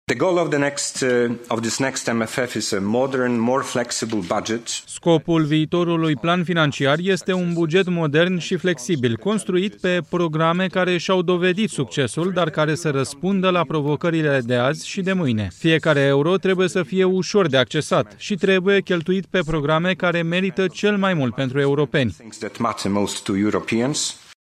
Azi, comisarul european pentru buget Piotr Serafin le-a prezentat europarlamentarilor propunerile Comisiei Europene pentru bugetul Uniunii pentru perioada 2028 – 2034.
Piotr Serafin, comisarul european pentru buget: „Fiecare euro trebuie cheltuit pe programe care merită cel mai mult pentru europeni”